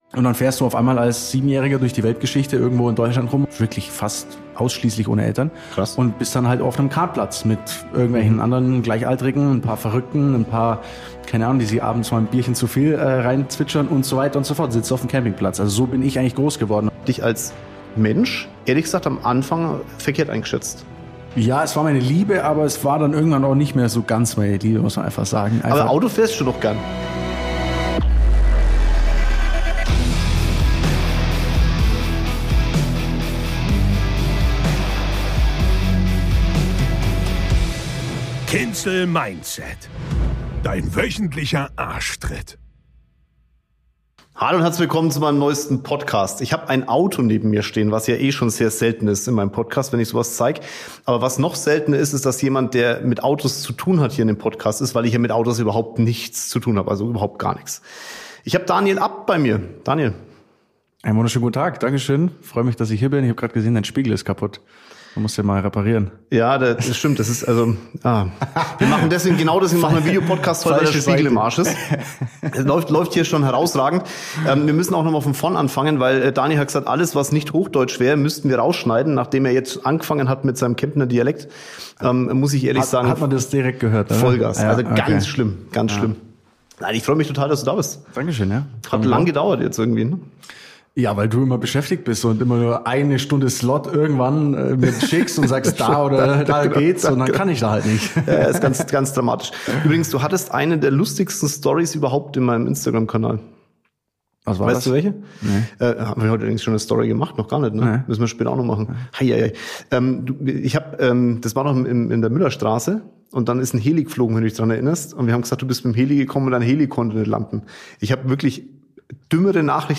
Erlebe ein spannendes Gespräch über das Leben eines Rennfahrers und Unternehmers und erfahre, was es bedeutet, in beiden Welten erfolgreich zu sein.